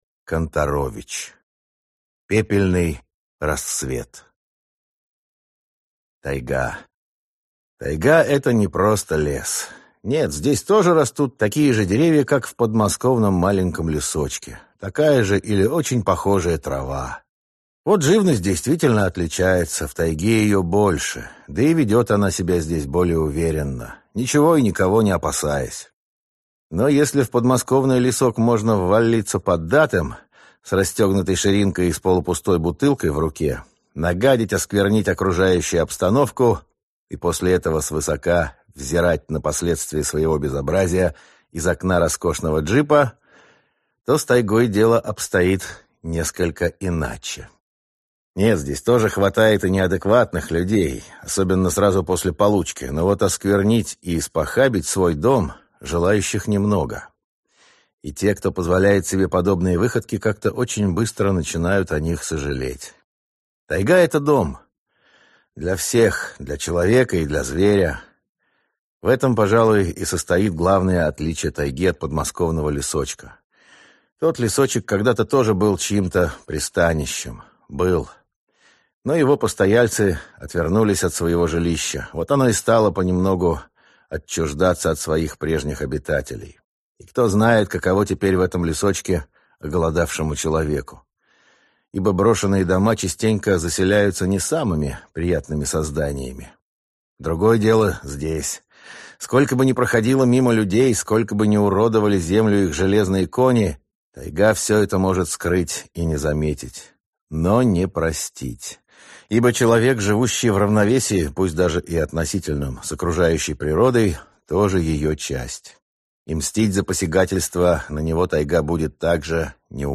Аудиокнига Пепельный рассвет | Библиотека аудиокниг
Прослушать и бесплатно скачать фрагмент аудиокниги